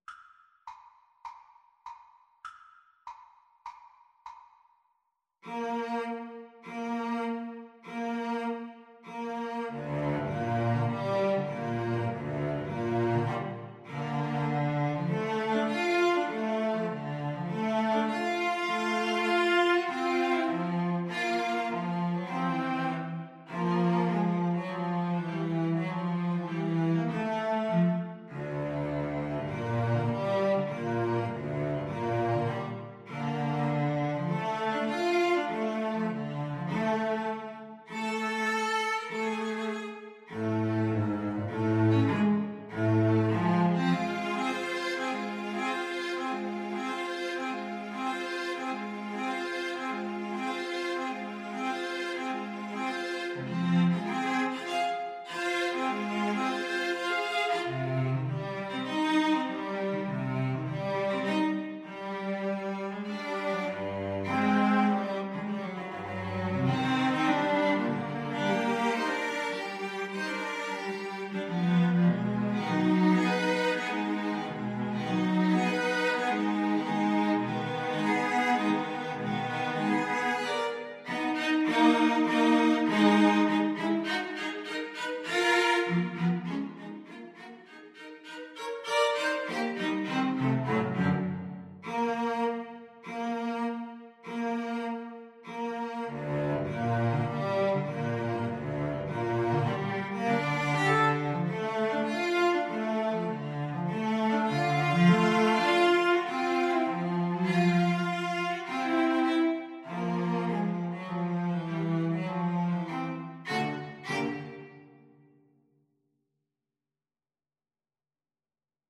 Cello Trio  (View more Advanced Cello Trio Music)
Classical (View more Classical Cello Trio Music)